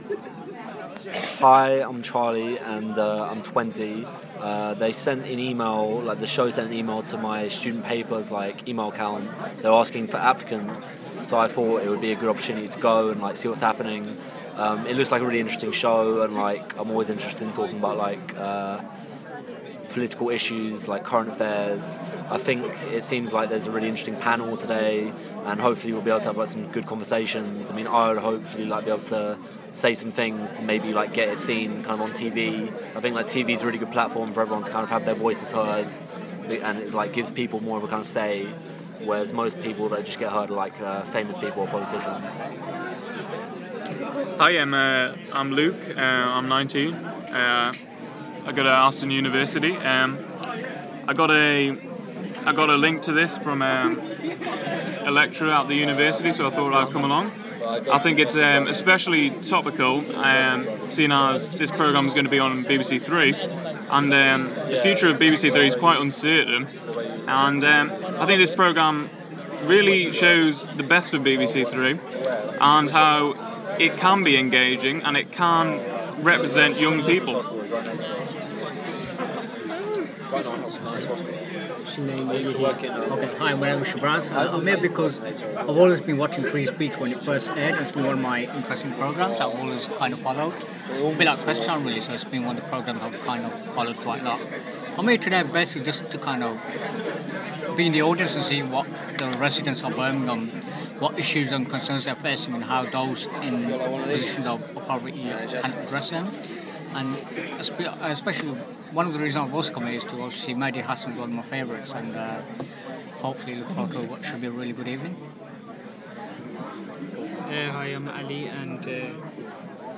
Young professionals and students explain why they are participating in a live broadcast of BBC Three's 'Free Speech' programme, being broadcast live from Birmingham Central Mosque. 12.03.14.